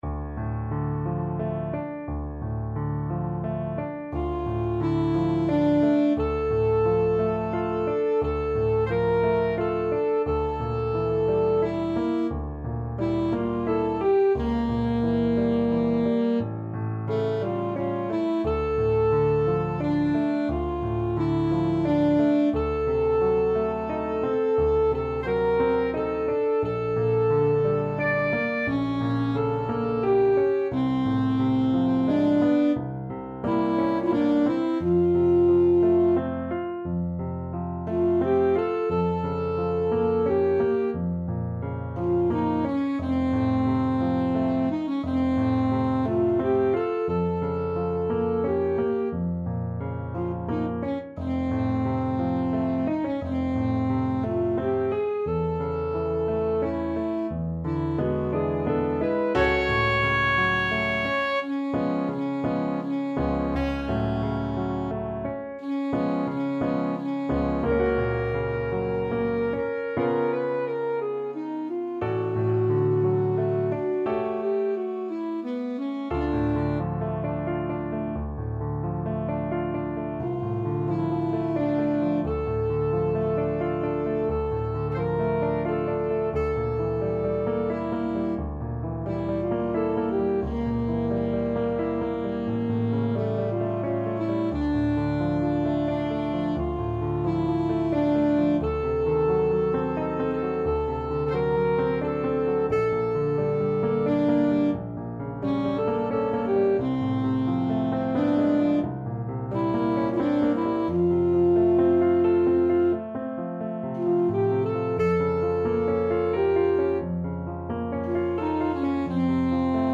Alto Saxophone
3/4 (View more 3/4 Music)
~ = 88 Malinconico espressivo
A4-D6
Classical (View more Classical Saxophone Music)